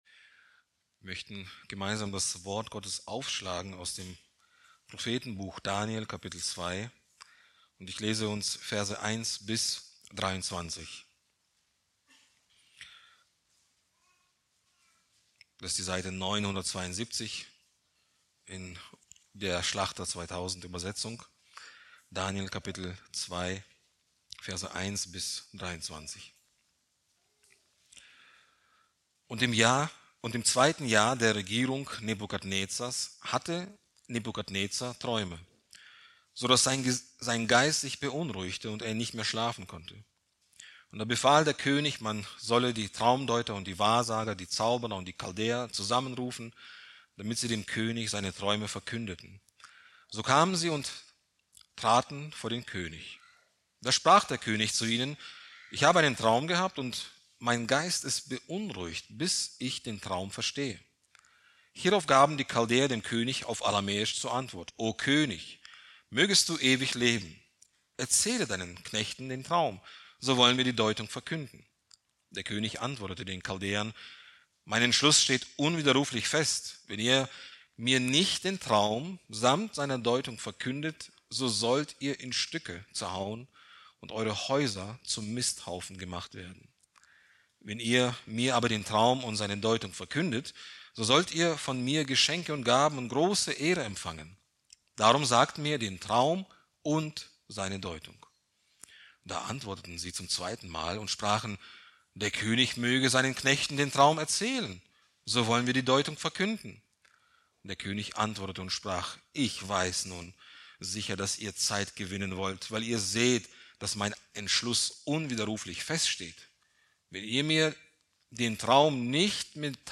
Predigt aus der Serie: "Weitere Predigten"